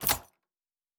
Locker 6.wav